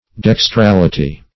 \Dex*tral"i*ty\